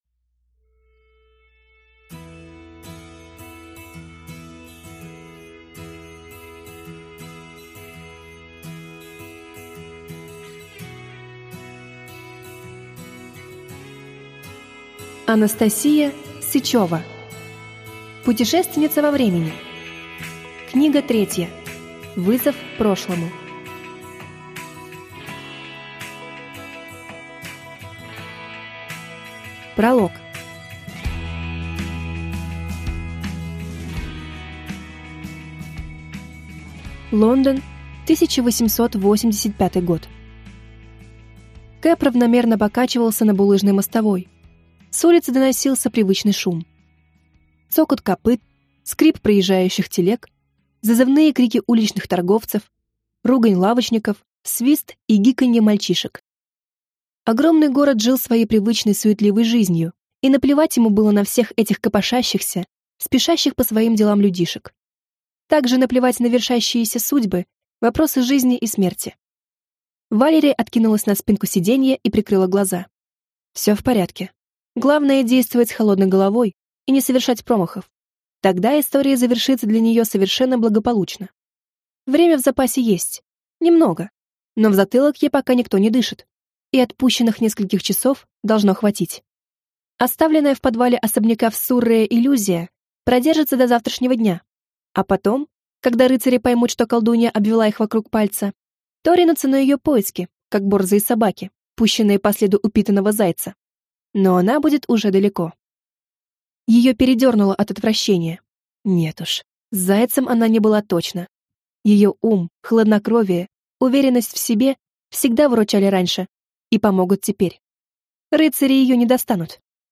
Аудиокнига Путешественница во времени. Вызов прошлому | Библиотека аудиокниг